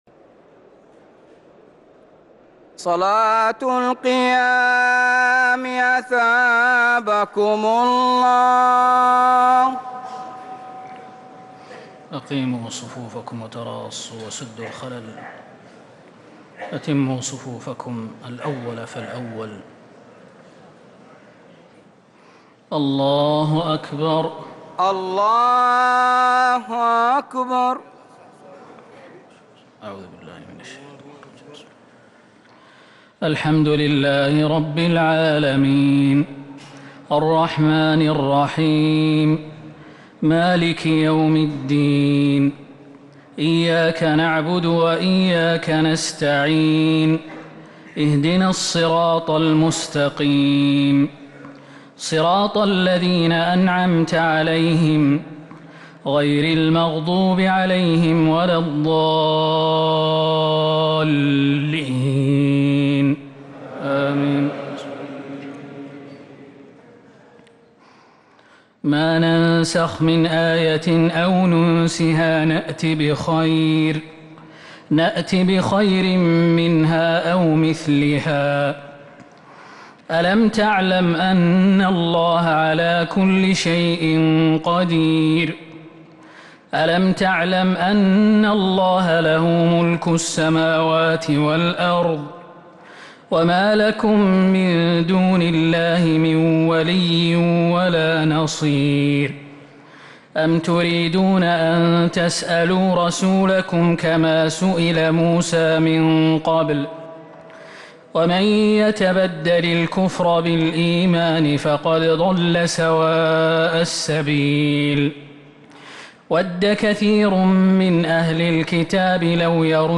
صلاة التراويح ليلة 2 رمضان 1444 للقارئ خالد المهنا - الثلاث التسليمات الأولى صلاة التراويح